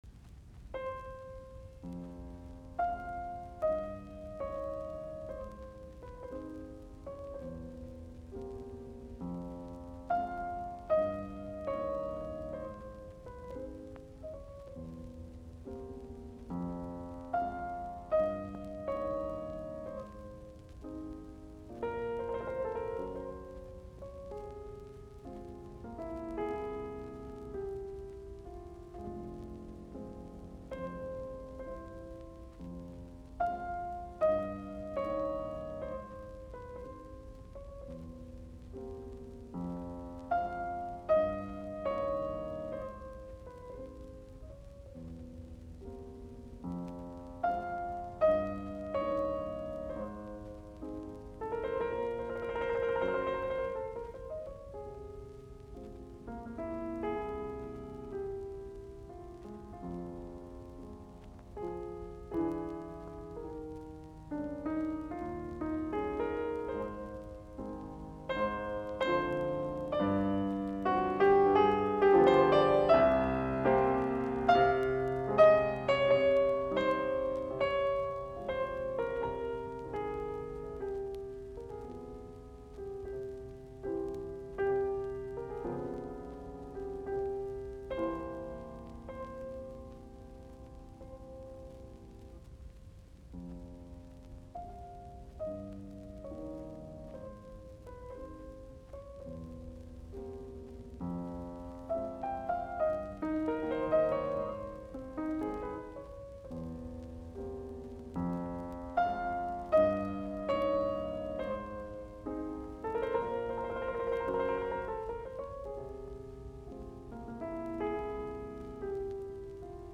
Nocturnet, piano, op55. Nro 1, f-molli
Soitinnus: Piano.